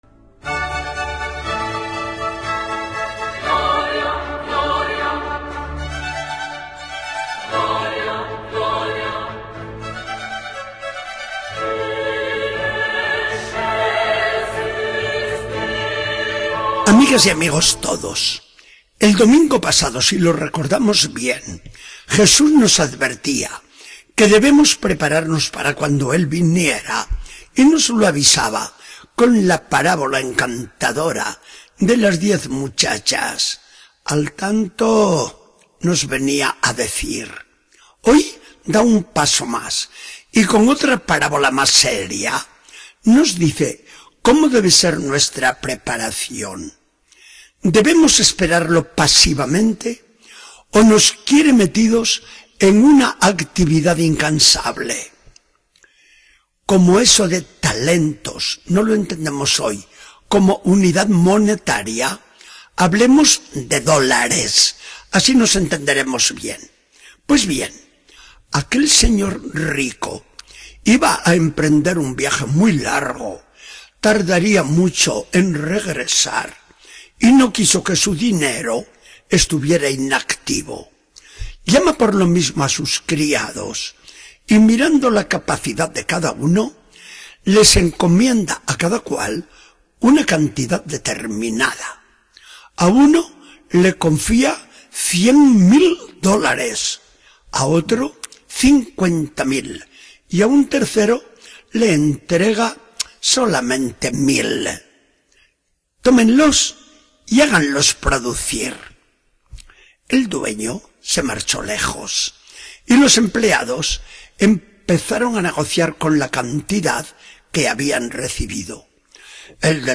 Charla del día 16 de noviembre de 2014. Del Evangelio según San Mateo 25, 14-30.